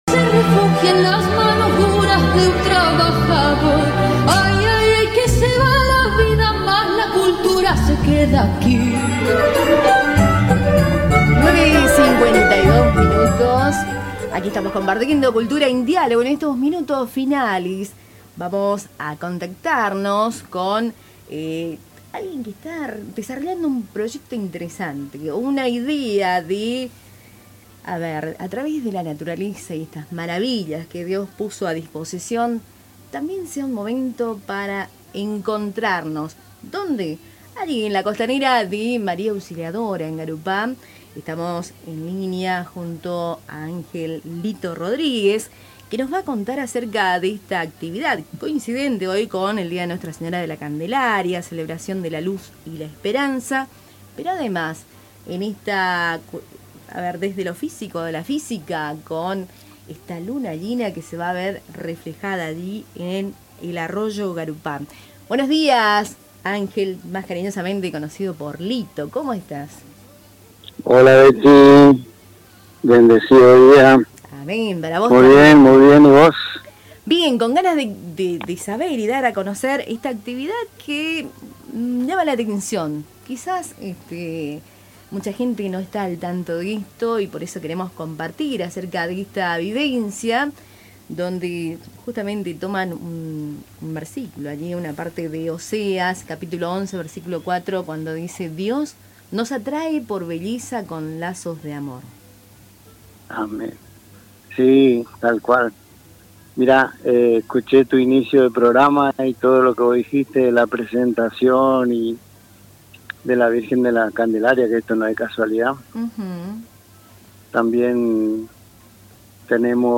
En comunicación con Cultura en Diálogo de Radio Tupambaé